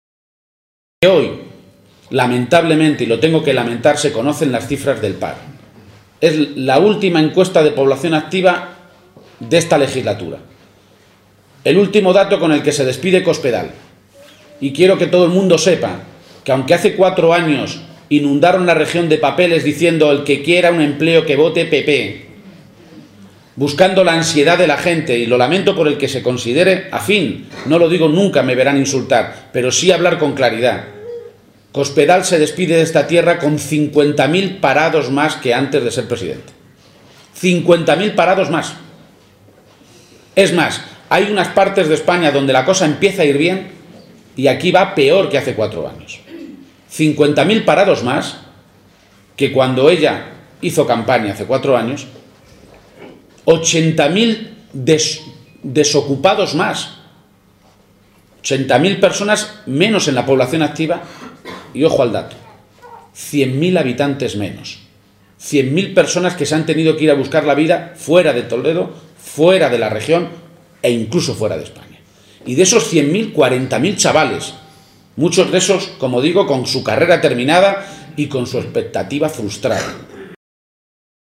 García-Page se pronunciaba de esta manera a preguntas de los medios de comunicación en la localidad toledana de La Guardia, después de participar en uno de los actos conmemorativos del Día del Libro.